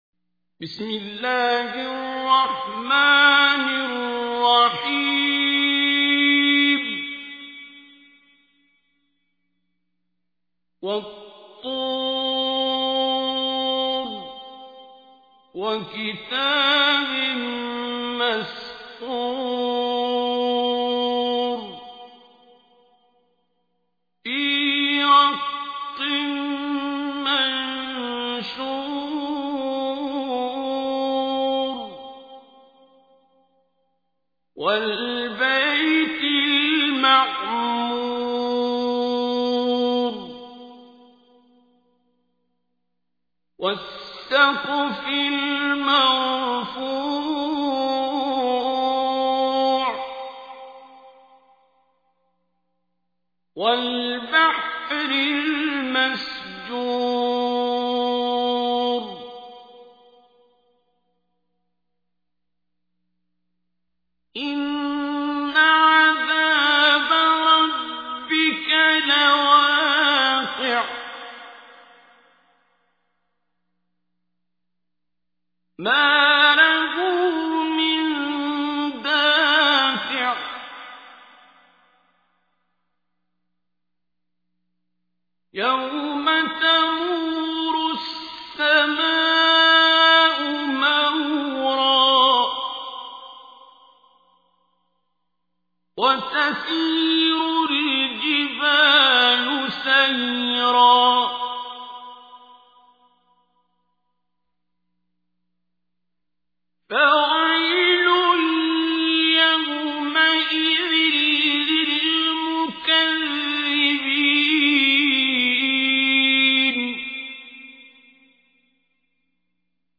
Listen online and download beautiful recitation of Surah At Tur recited by Qari Abdul Basit As Samad.